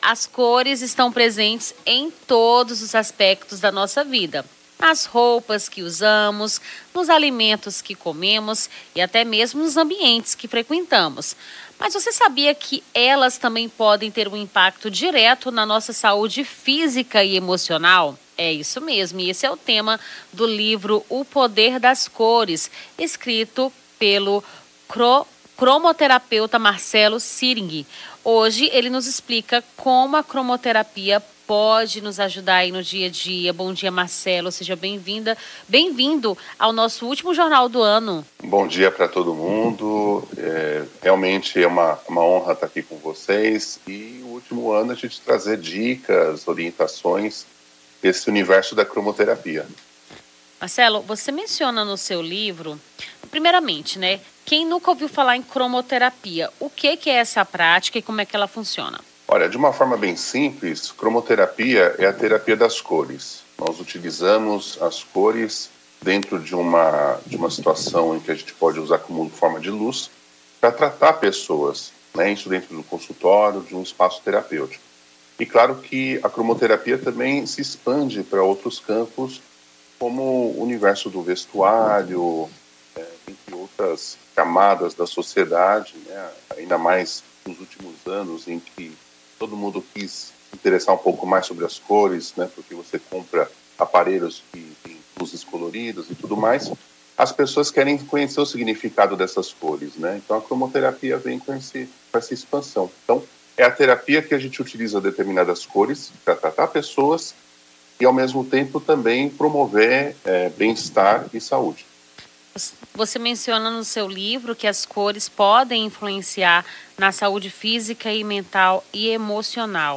Rio Branco